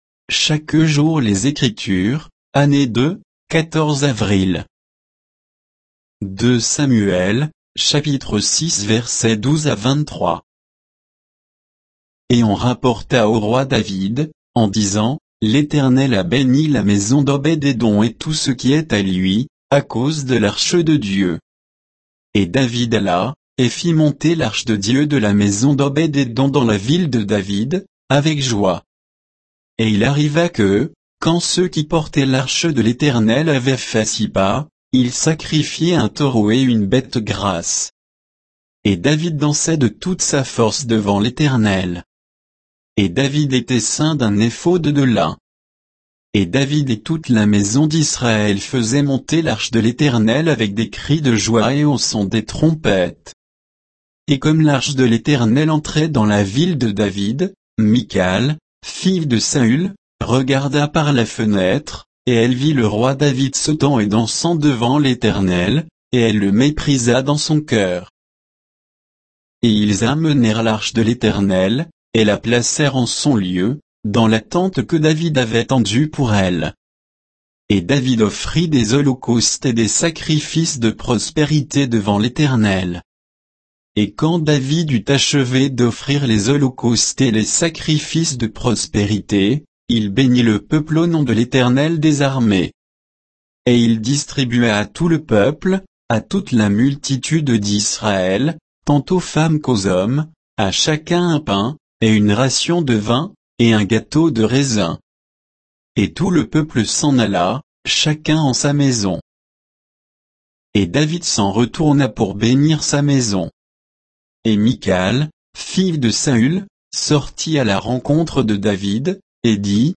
Méditation quoditienne de Chaque jour les Écritures sur 2 Samuel 6, 12 à 23